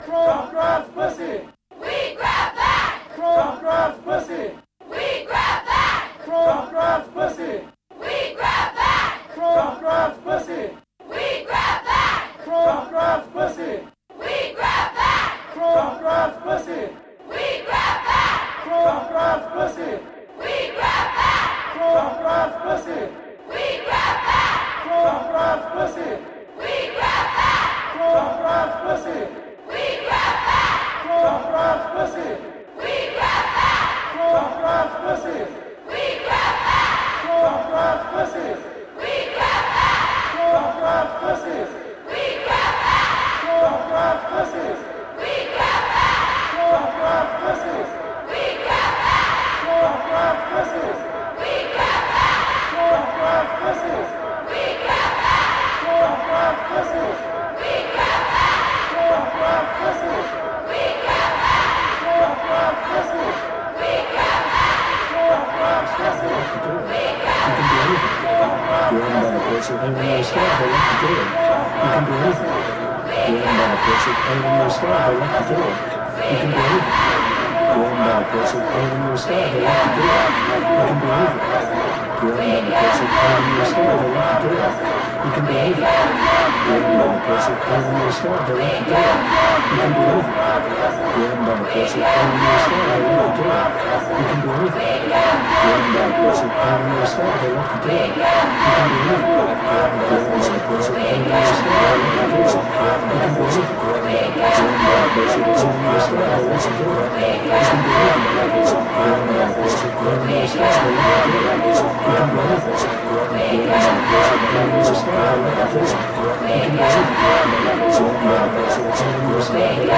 "Trump grabs pussy" protest reimagined by Cities and Memory.
To many people, that's now just another soundbite, robbed by repetition of its abhorrence. This piece asks you to listen, listen again and keep listening - the President of the USA actually said THAT, and we must never forget it, or allow someone in a position of power to behave in this manner again.